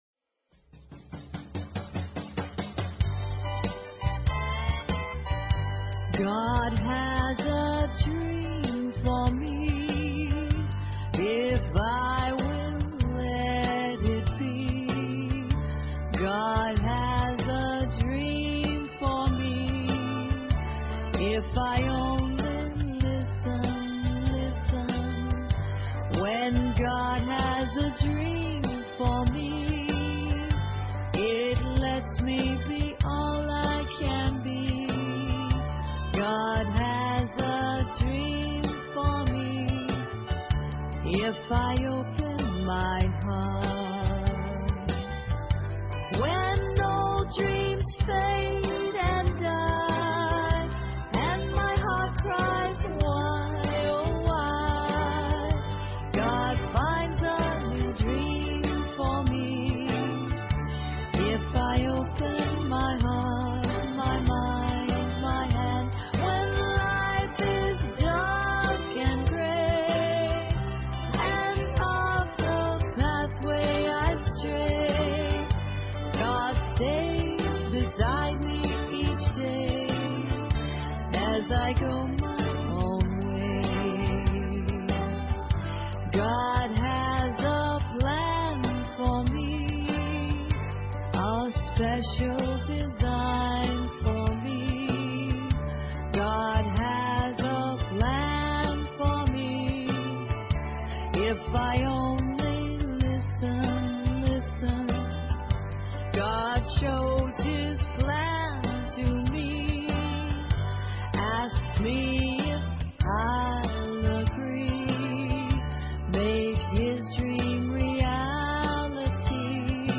Talk Show Episode, Audio Podcast, Inner_Garden_Online_Chapel and Courtesy of BBS Radio on , show guests , about , categorized as